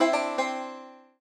banjo_c1e1d1c1.ogg